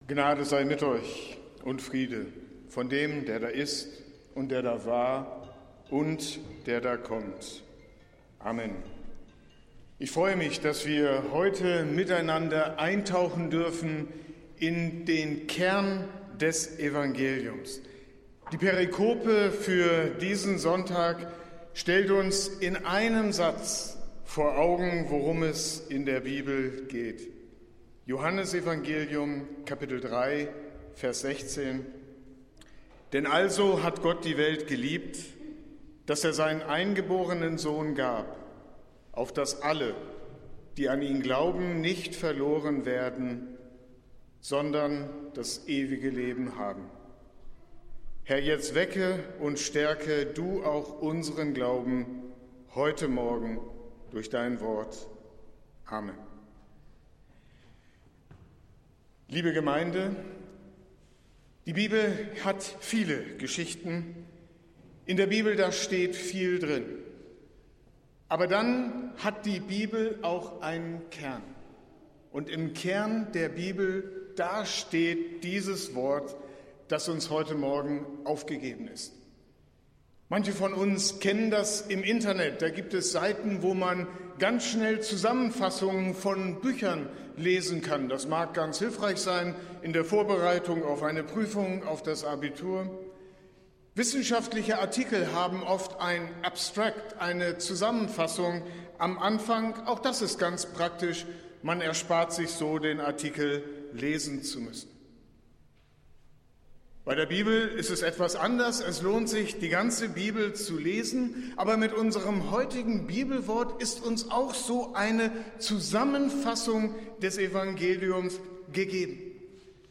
Predigt des Gottesdienstes aus der Zionskirche am Sonntag, den 16. März 2025